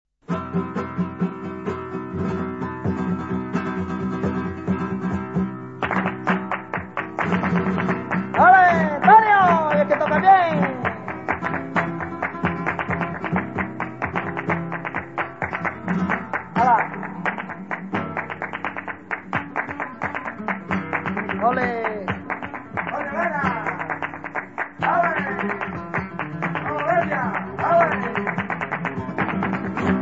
Bulerias de Cadiz